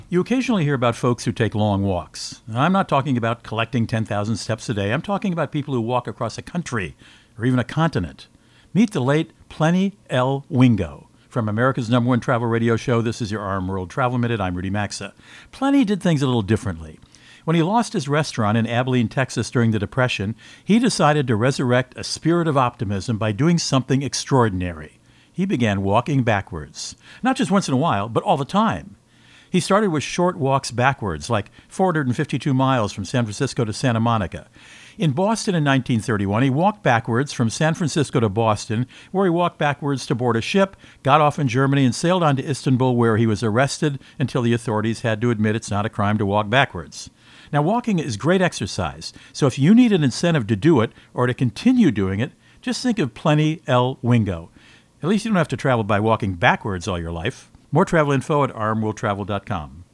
Co-Host Rudy Maxa | Backwards Walking Man